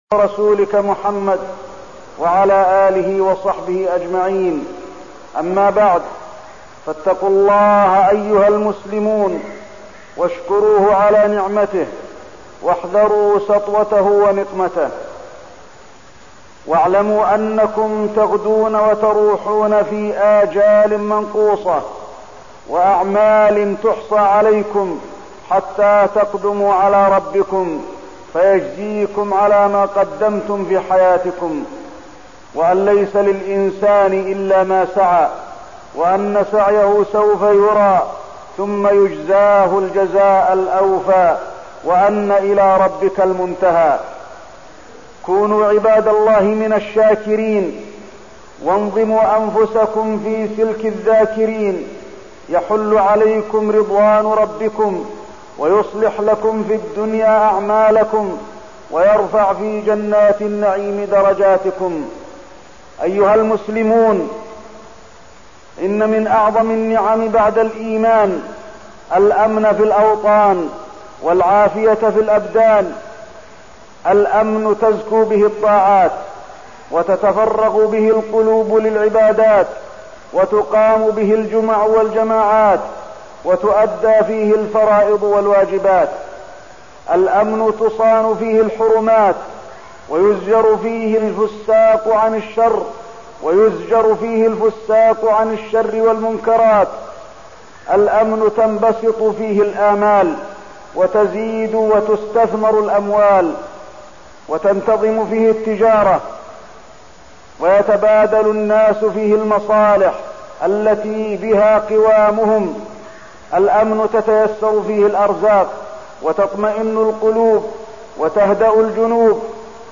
تاريخ النشر ١ ربيع الثاني ١٤١٤ هـ المكان: المسجد النبوي الشيخ: فضيلة الشيخ د. علي بن عبدالرحمن الحذيفي فضيلة الشيخ د. علي بن عبدالرحمن الحذيفي نعمة الأمن وتحريم الدش